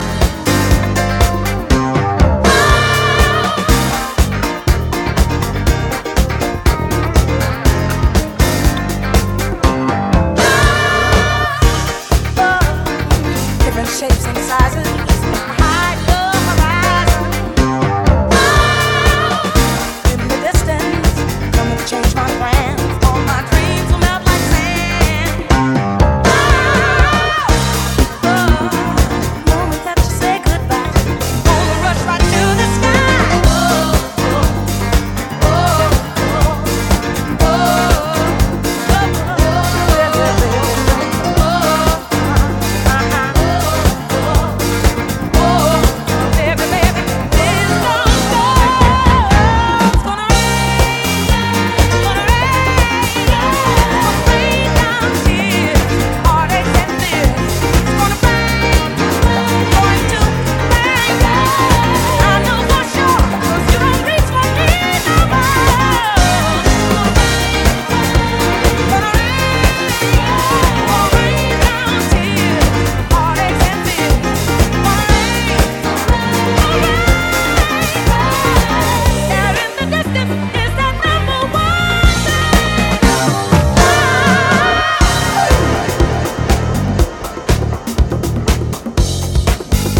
Disco reworks